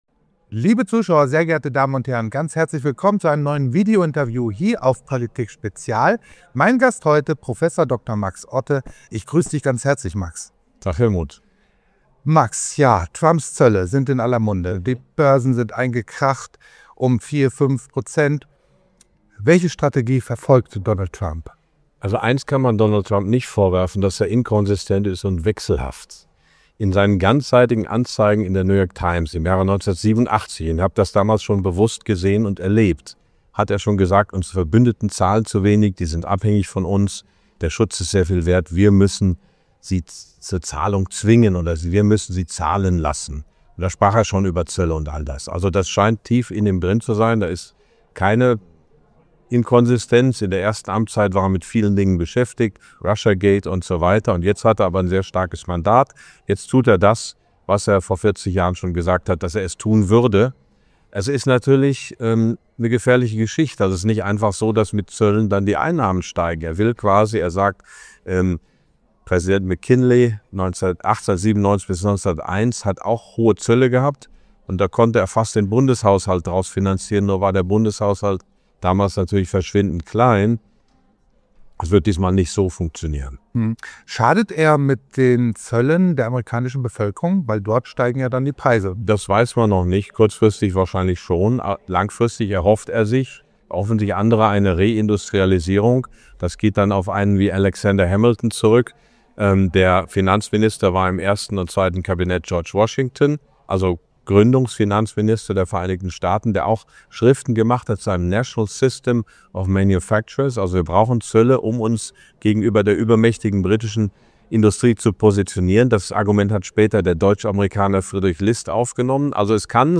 In diesem Interview analysiert Prof. Dr. Max Otte die wirtschaftlichen Folgen von Trumps Zollpolitik und die aktuelle Lage der deutschen Wirtschaft. Er bewertet die Koalitionsverhandlungen zwischen CDU und SPD und gibt eine Einschätzung zur Zukunft deutscher Unternehmen.